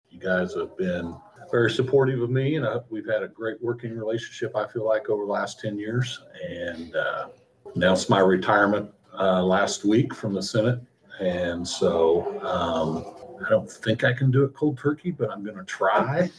The Emporia Republican was at Monday’s Geary County Commission meeting.